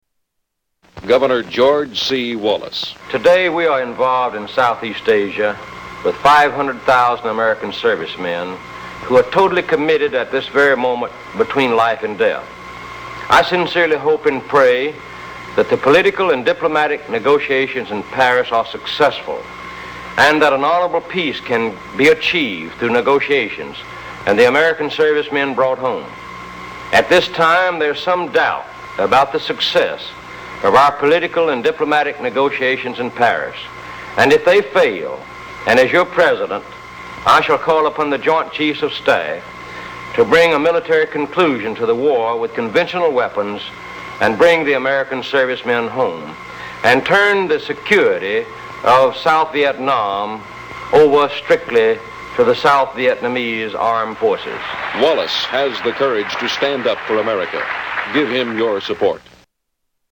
Wallace 1968 Campaign Ad